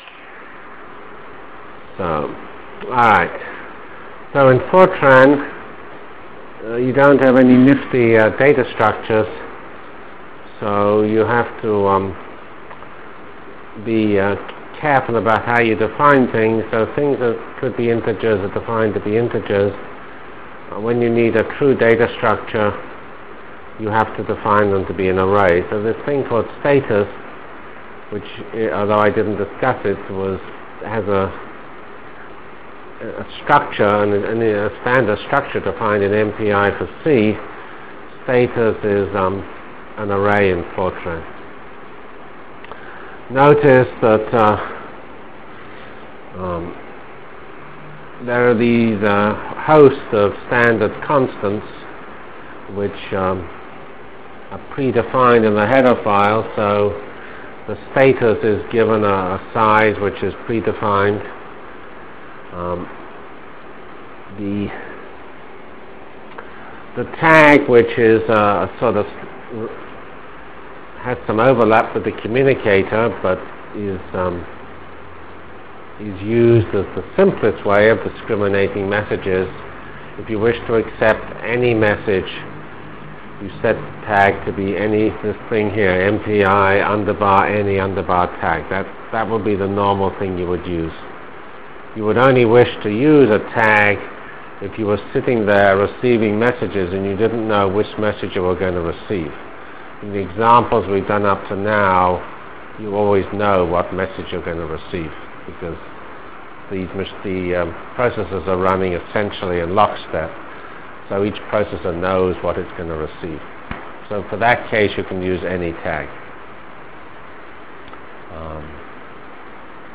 Lecture of November 7 - 1996